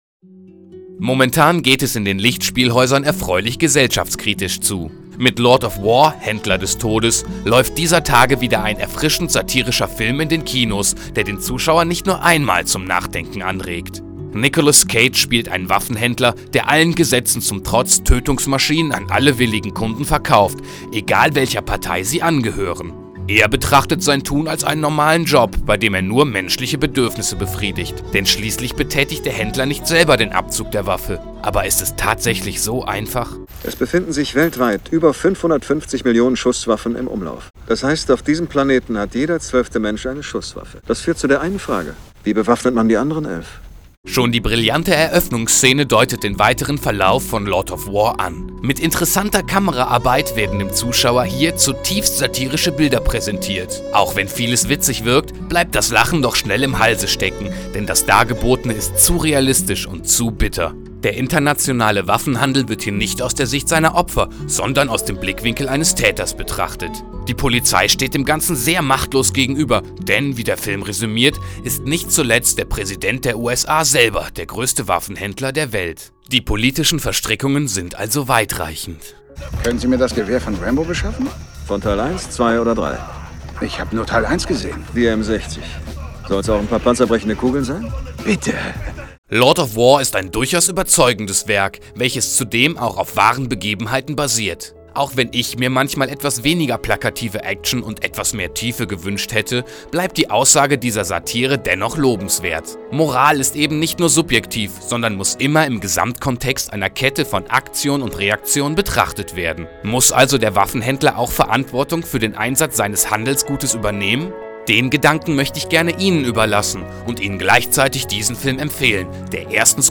kinotipp_lordofwar.mp3